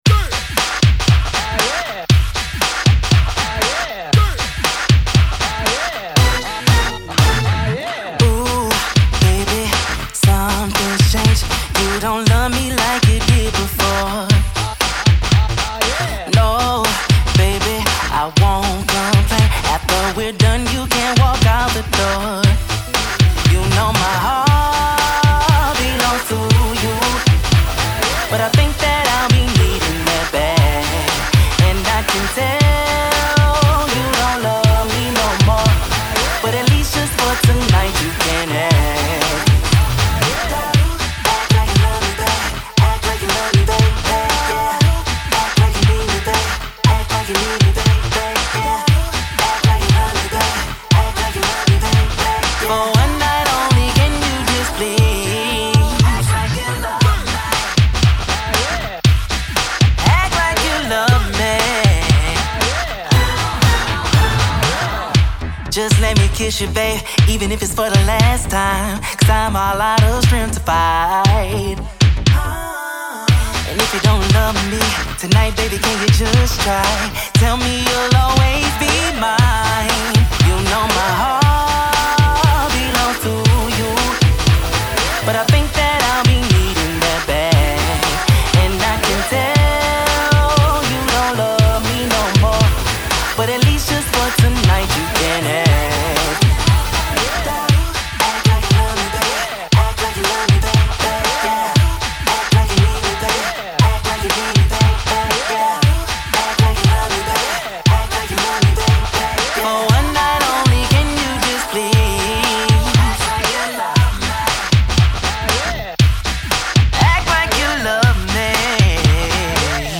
SINGLESR&B/SOUL